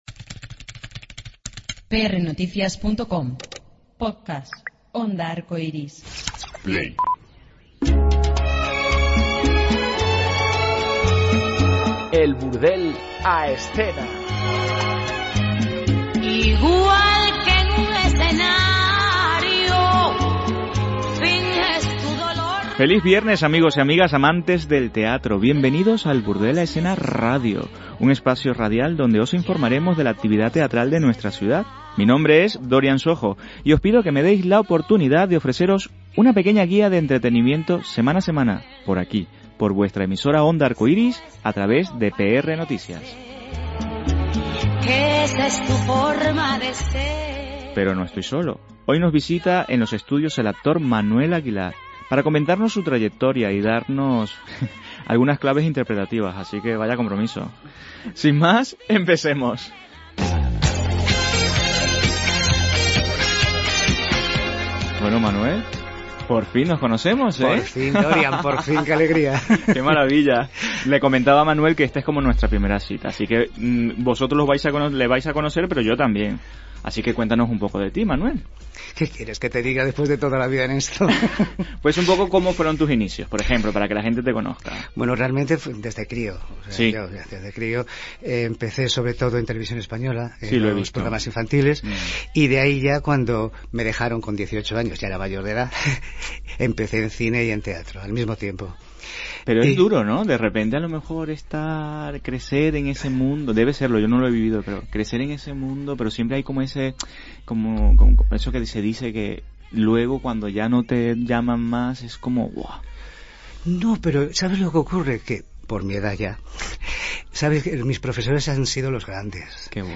Además, y junto a nuestra entrevista de la semana, os queremos recomendar dos propuestas teatrales para que, y ahora ya no hay excusas…